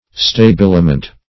Search Result for " stabiliment" : The Collaborative International Dictionary of English v.0.48: Stabiliment \Sta*bil"i*ment\ (st[.a]*b[i^]l"[i^]*ment), n. [L. stabilimentum, fr. stabilire to make firm or stable, fr. stabilis.